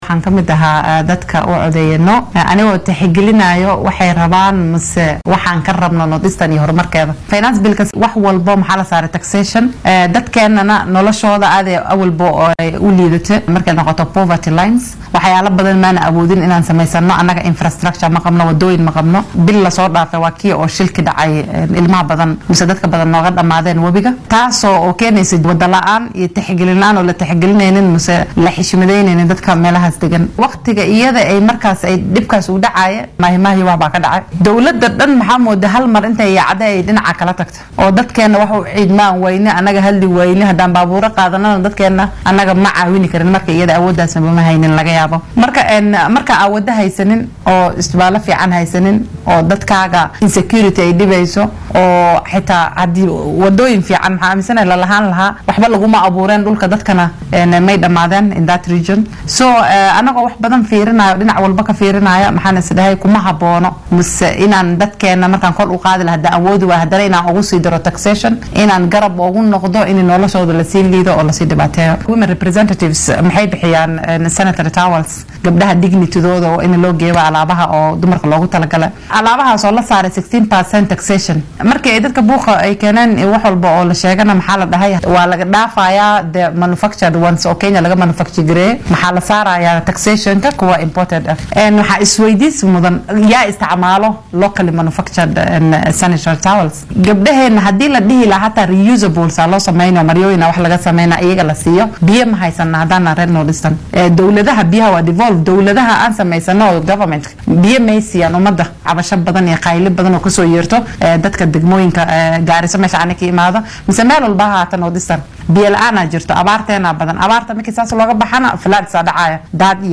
DHAGEYSO:Wakiilka haweenka ee Garissa oo faahfaahisay go’aankii ay uga soo horjeedsatay hindise sharciyeedka maaliyadda